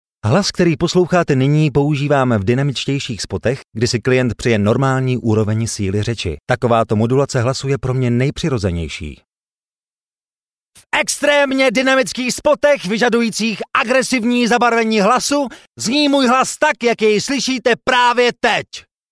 Sprecher tschechisch für TV / Rundfunk / Industrie.
Sprechprobe: Industrie (Muttersprache):
Professionell voice over artist from Czech.